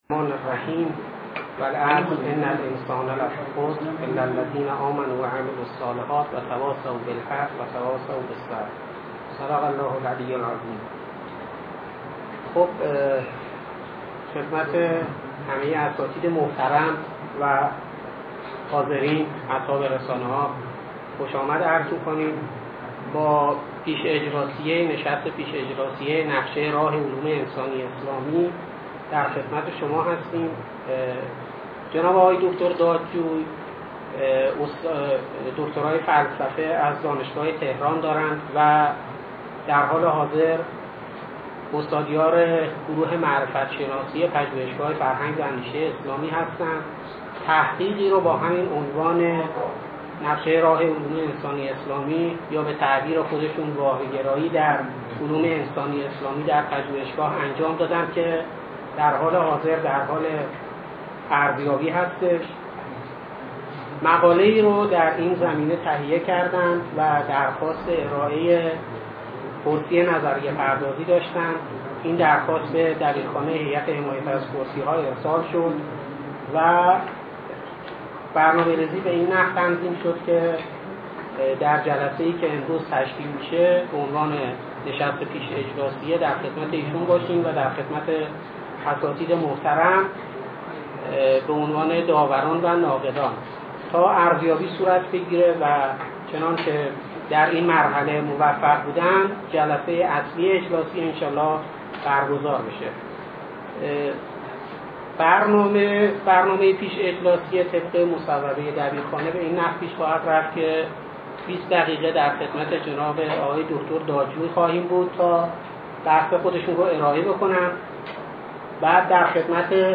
سخنرانی
این نشست در واقع جلسهی دفاع این نظریه بود که در خرداد ماه ۹۳ در پژوهشگاه فرهنگ و اندیشهی اسلامی برگزار شد.